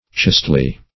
Chastely \Chaste"ly\, adv.